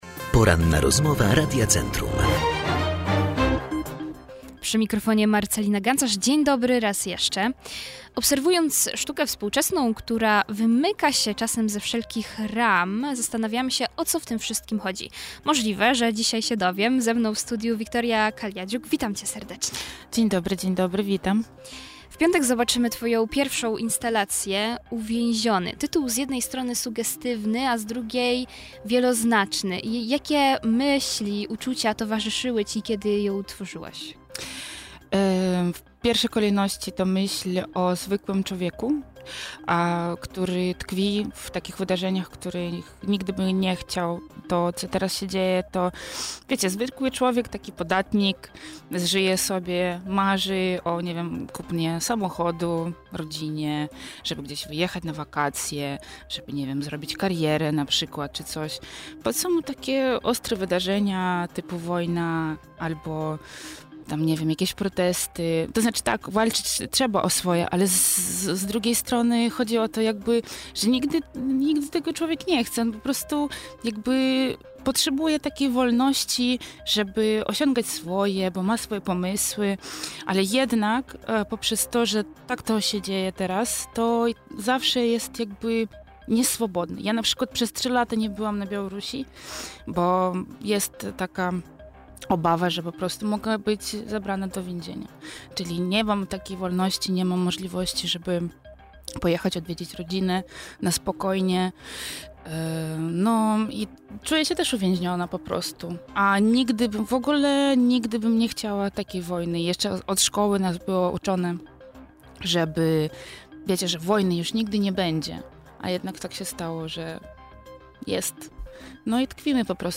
Cała rozmowa z artystką jest dostępna poniżej.